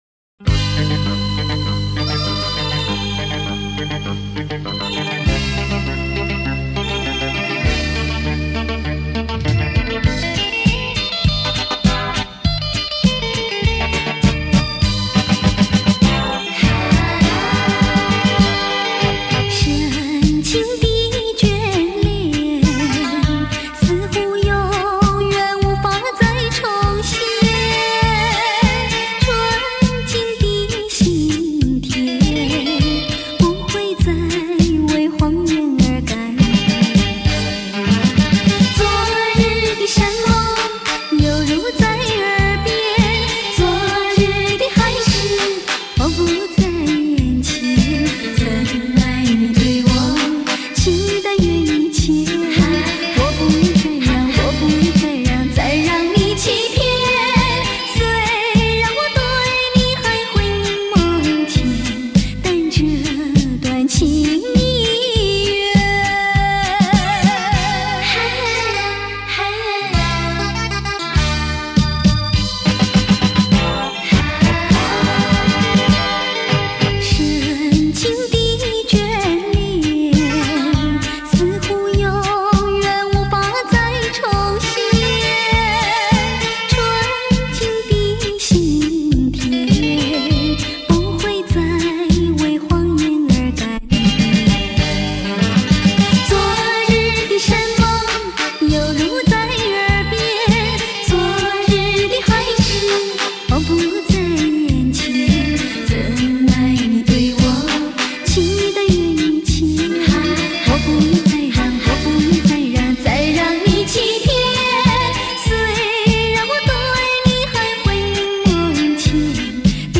转制无损格式也比较成功。音质基本保持原味。
资源类型：卡带原转wav